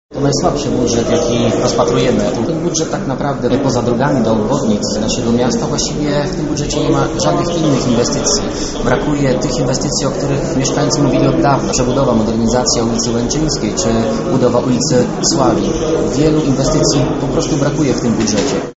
O wydatkach miasta na 2014 rok mówi Sylwester Tułajew Przewodniczący Klubu Radnych Prawa i Sprawiedliwości w Lublinie.